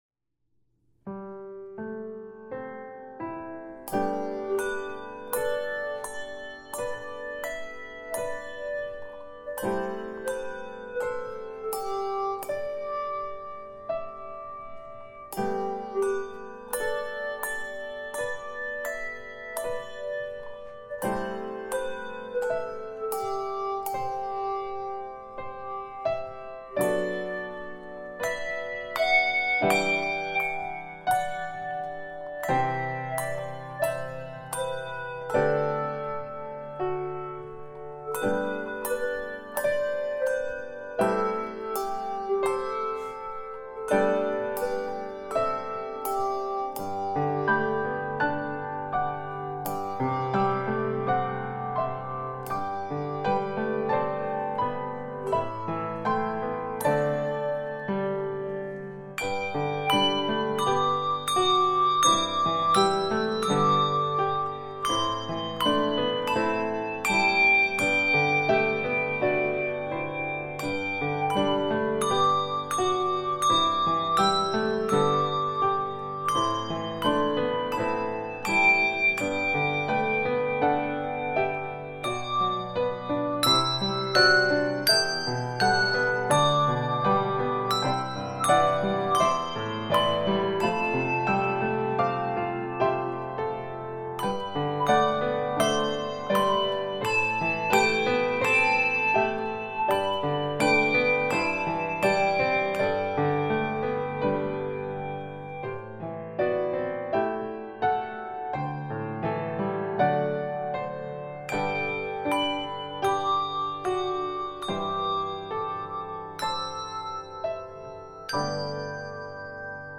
Review: We have great news for handbell soloists!
arranged for handbell soloist and keyboard accompaniment